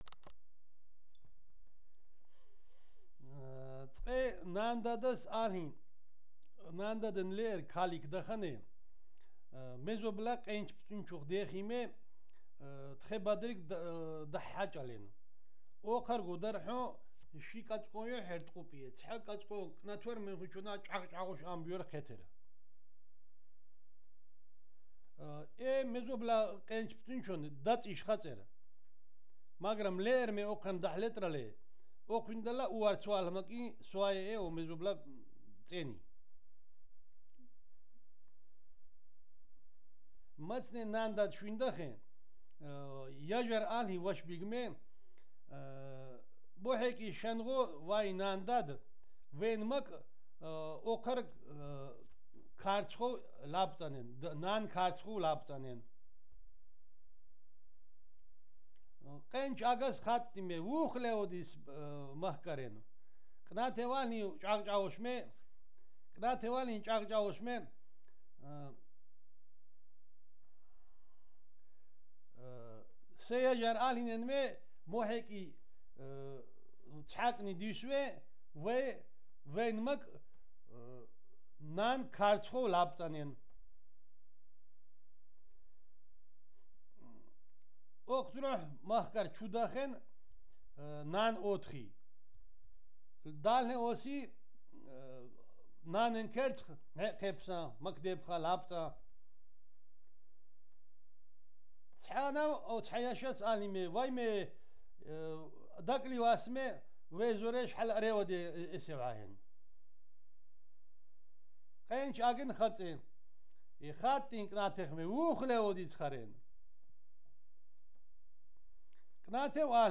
digital wav file recorded at 48 kHz/24 bit on Marantz PMD561 solid state recorder
Zemo Alvani, Kakheti, Georgia